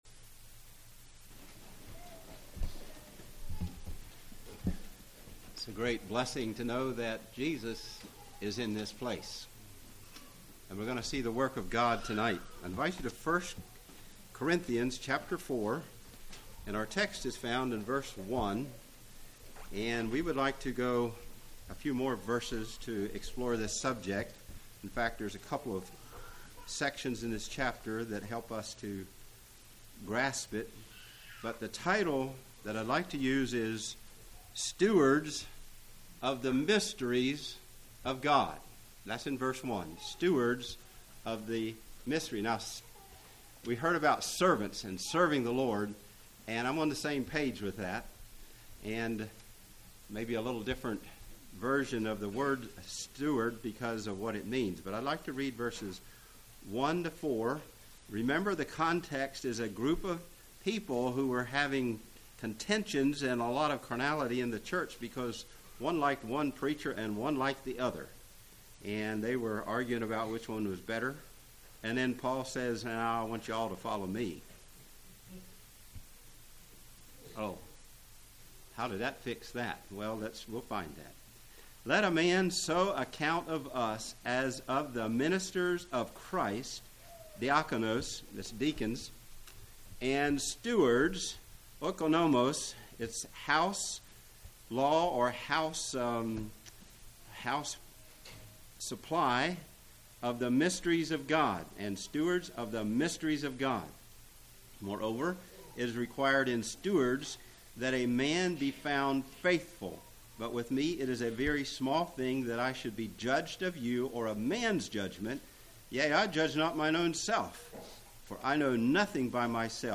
Congregation: Pensacola